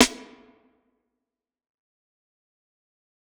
Destroy - Perc Drumin.wav